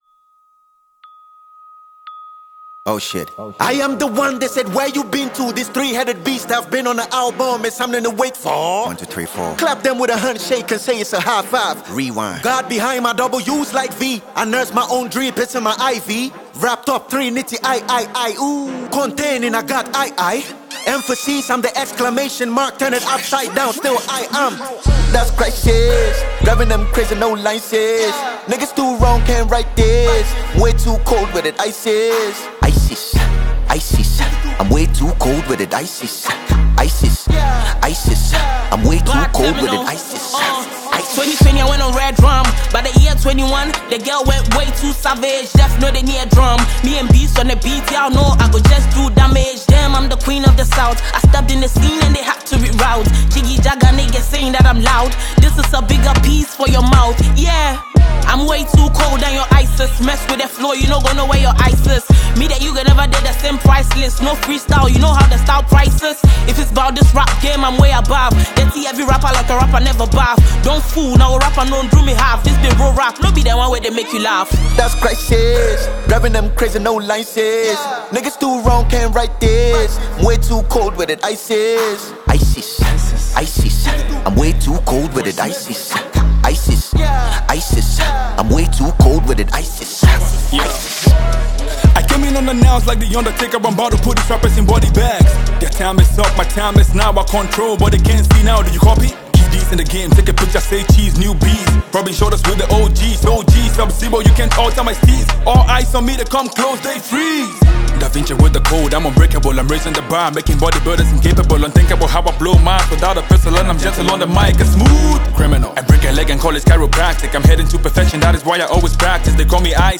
Ghanaian rap is alive
hardcore Cypher
talented rappers